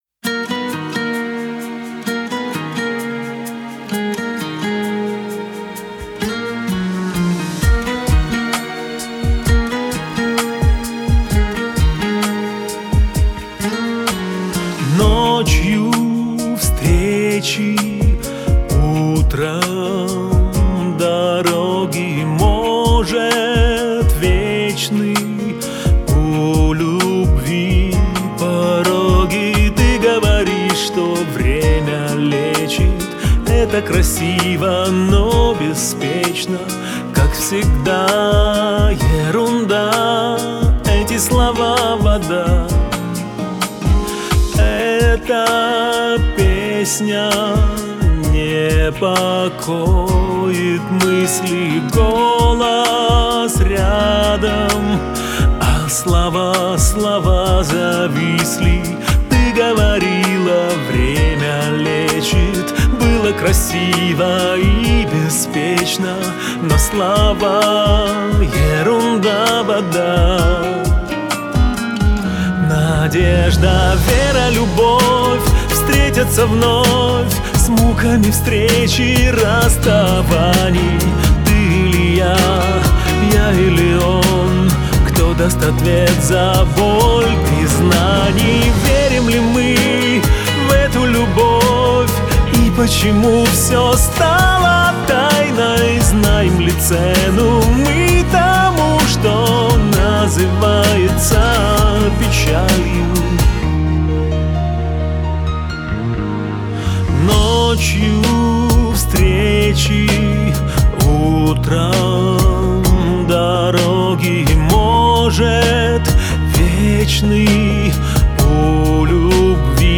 своим чувственным вокалом и искренним исполнением